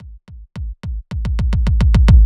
RollFade.wav